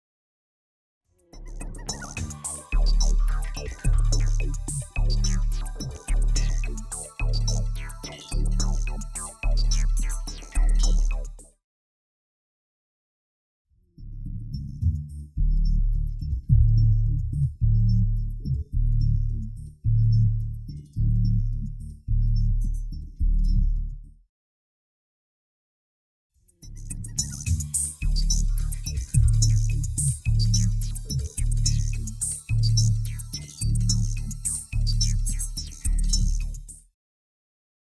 Such combination is common for electronic music.